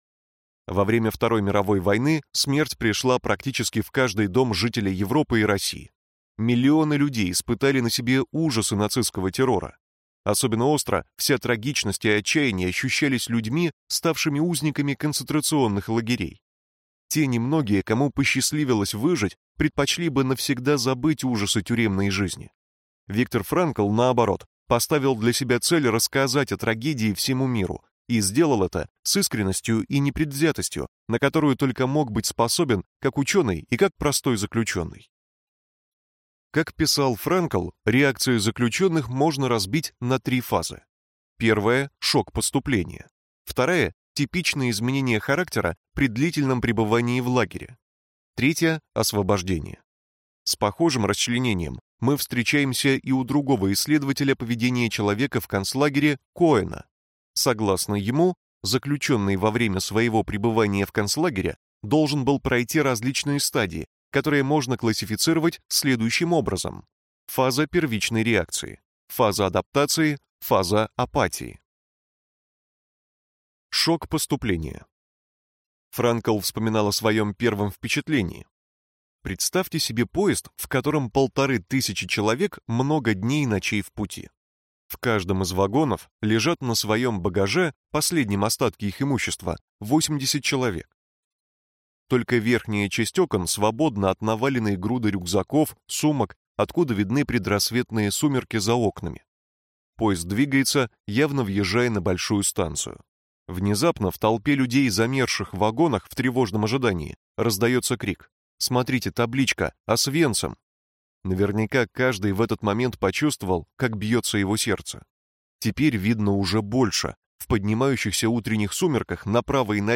Аудиокнига Человек в поисках смысла. Краткая версия | Библиотека аудиокниг